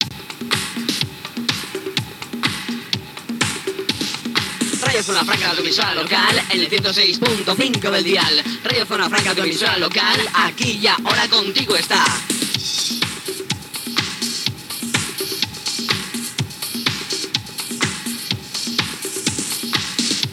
Indicatiu cantat de l'emissora